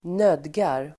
Uttal: [²n'öd:gar]